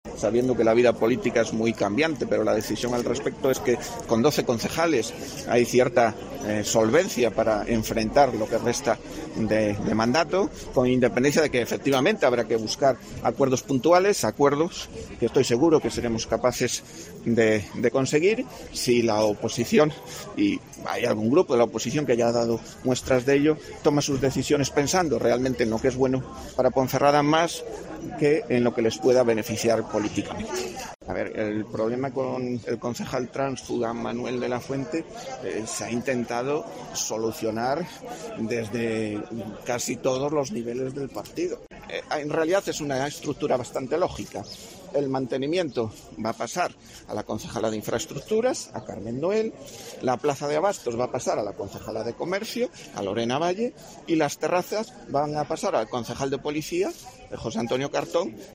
Escucha aquí las palabras del Olegario Ramón